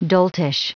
Prononciation du mot doltish en anglais (fichier audio)
Prononciation du mot : doltish